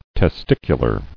[tes·tic·u·lar]